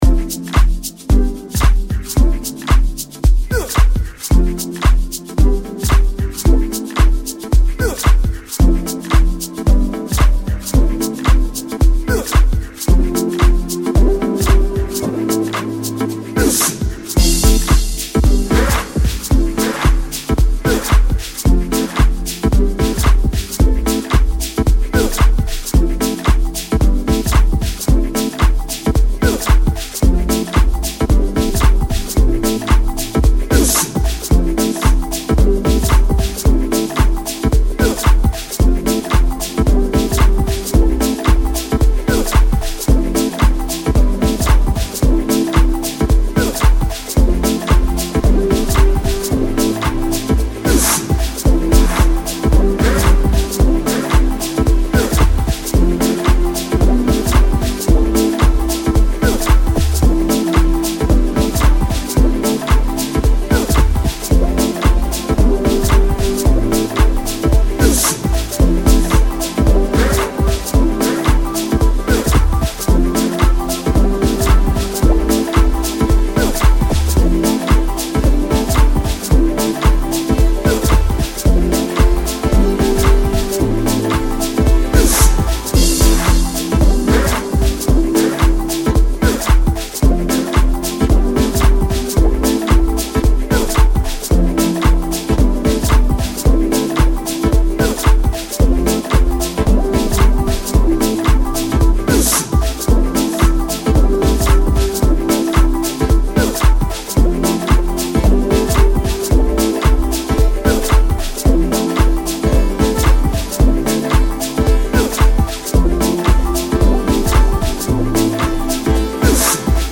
Embark on a soulful musical journey
rich melodies and heartfelt vocals